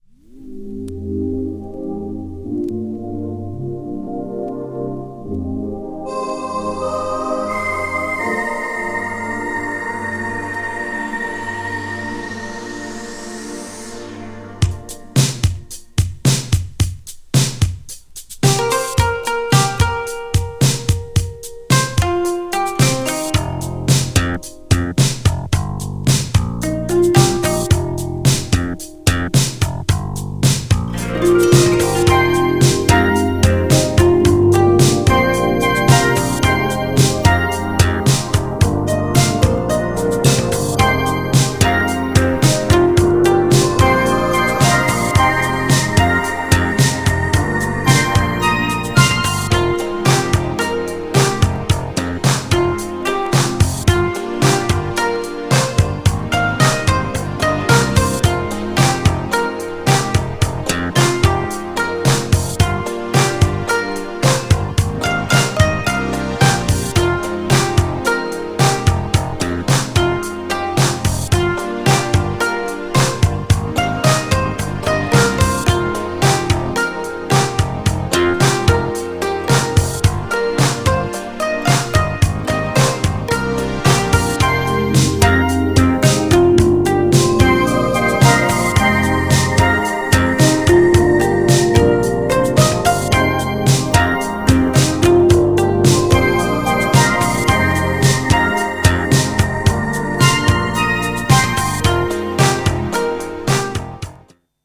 意図的に和楽器を取り入れた日本人にとっても新感覚なFUSION!!
GENRE Dance Classic
BPM 111〜115BPM